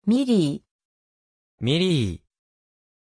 Pronunciation of Millie
pronunciation-millie-ja.mp3